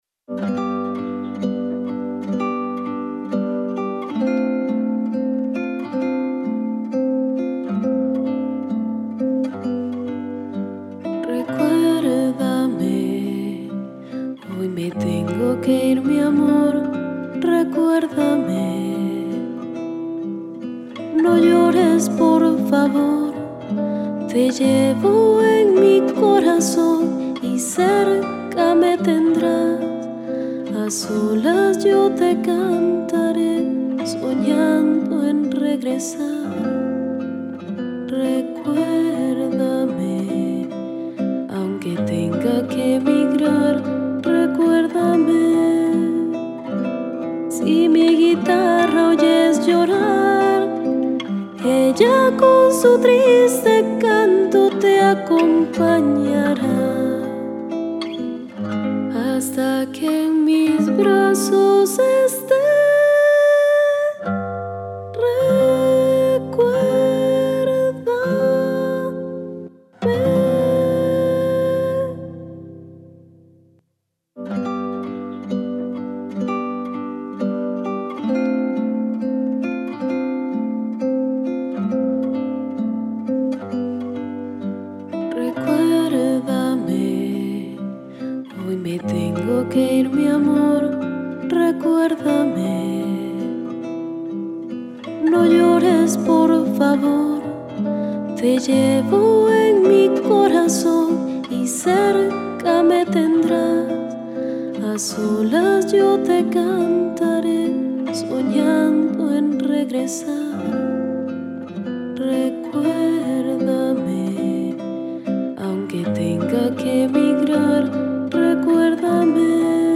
Do Mayor